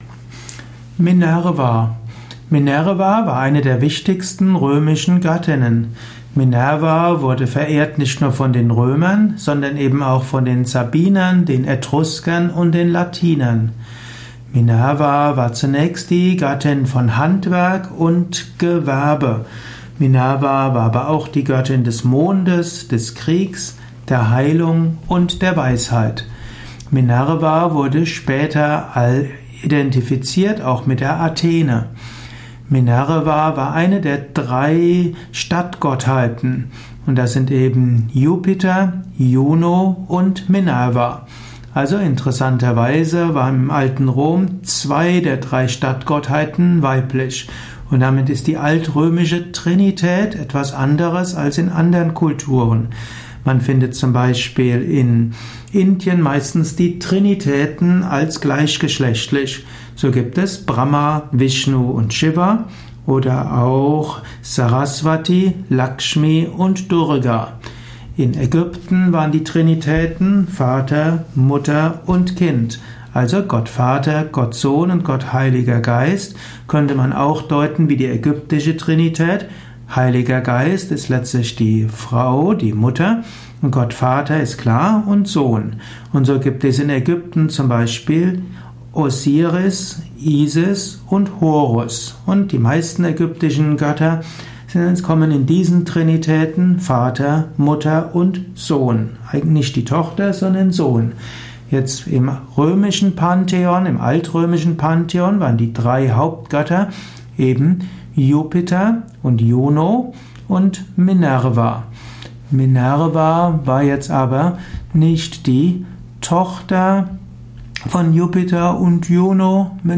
Ausführungen über die Stellung von Minerva in der römischen Mythologie, im römischen Pantheon. Welche Bedeutung hat Göttin Minerva nicht nur historisch, sondern auch als spirituelles Prinzip? Dies ist die Tonspur eines Videos, zu finden im Yoga Wiki.